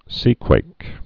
(sēkwāk)